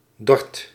Summary Description Nl-Dordt.ogg Dutch pronunciation for "Dordt" — male voice.